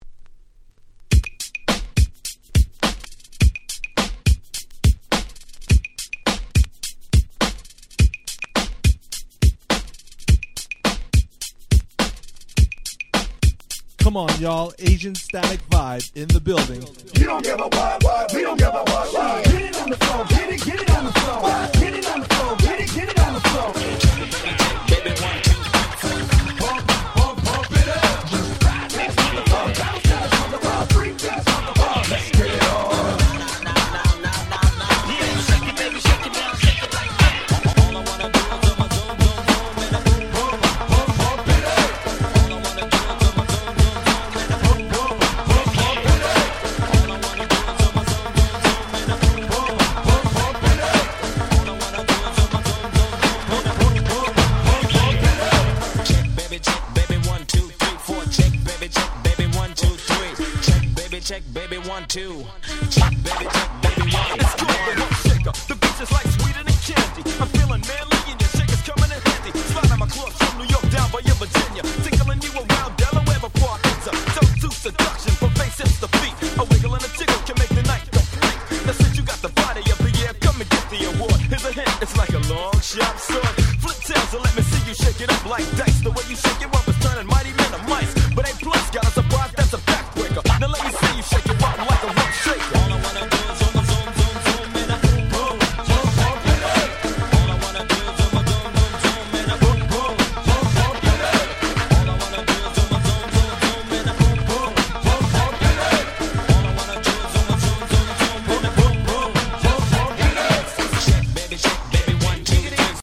White盤オンリーのアゲアゲParty Tracks/勝手にRemix物！！